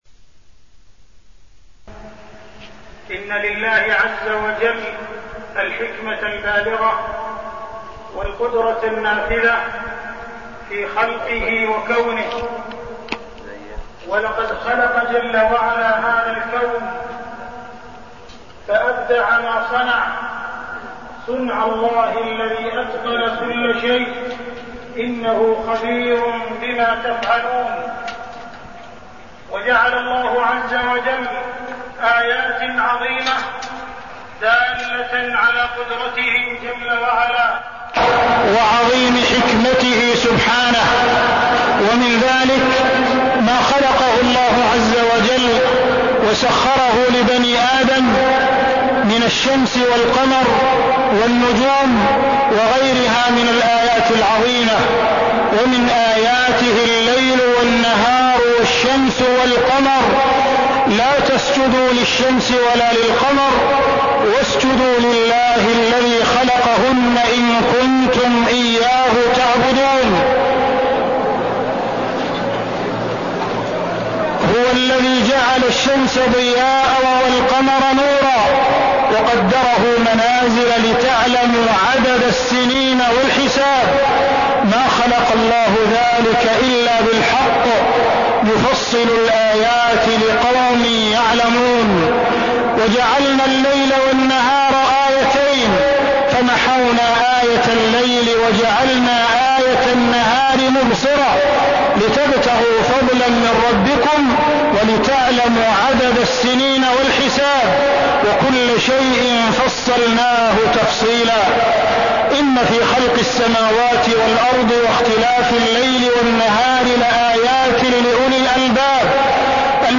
تاريخ النشر ١٥ ذو القعدة ١٤١٦ هـ المكان: المسجد الحرام الشيخ: معالي الشيخ أ.د. عبدالرحمن بن عبدالعزيز السديس معالي الشيخ أ.د. عبدالرحمن بن عبدالعزيز السديس تدل على قدرة الله